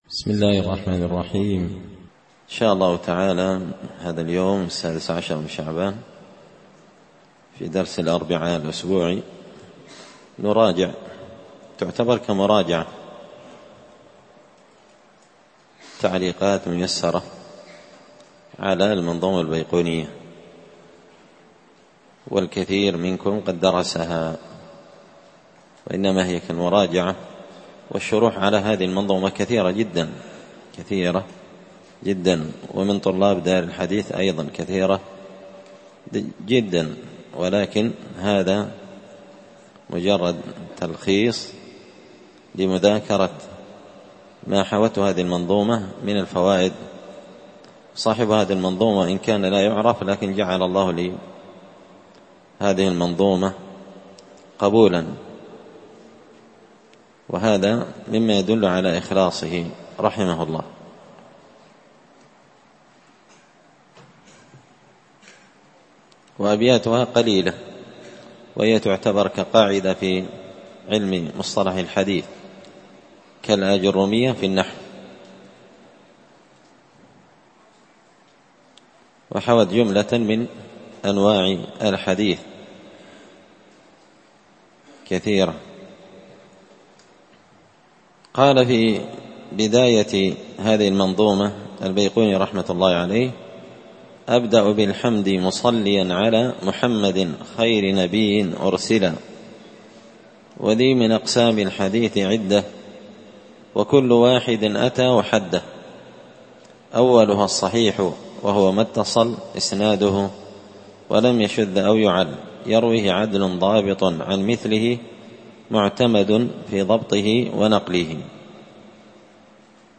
(1)الدرس الأول من التعليقات المختصرة على البيقونية